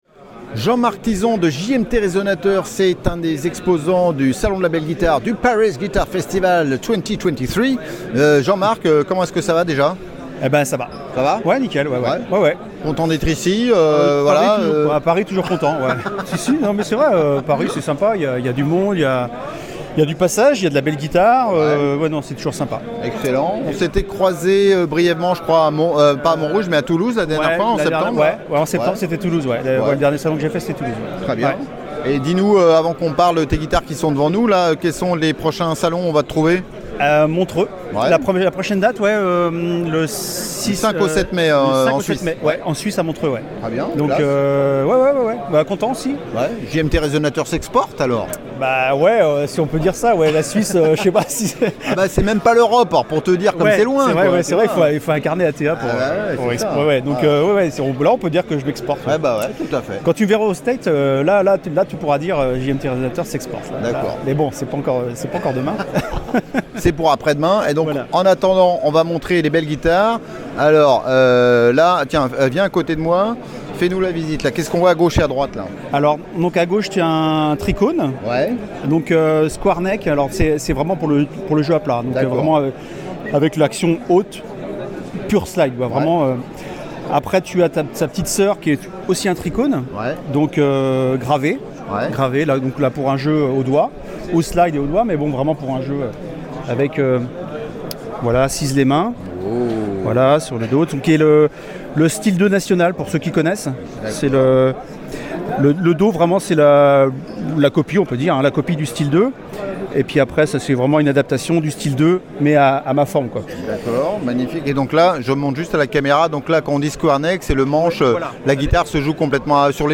JMT Résonateur, interview